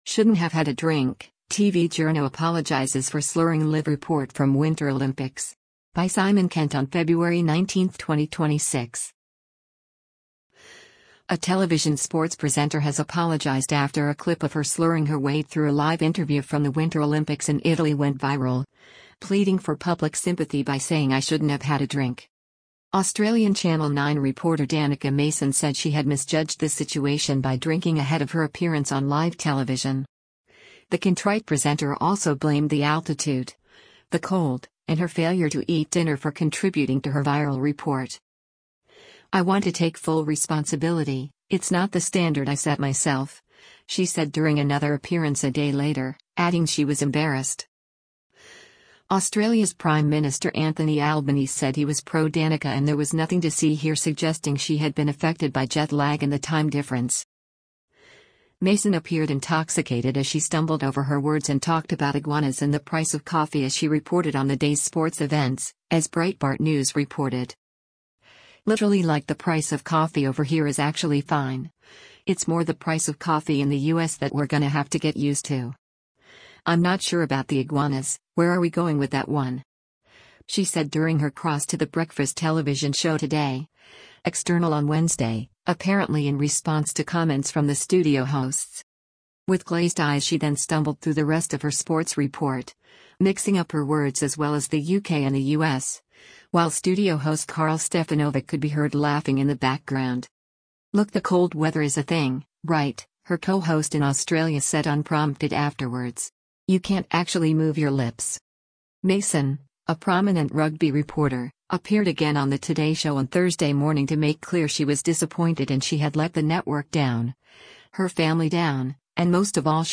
A television sports presenter has apologised after a clip of her slurring her way through a live interview from the Winter Olympics in Italy went viral, pleading for public sympathy by saying “I shouldn’t have had a drink.”
With glazed eyes she then stumbled through the rest of her sports report, mixing up her words as well as the UK and the U.S., while studio host Karl Stefanovic could be heard laughing in the background.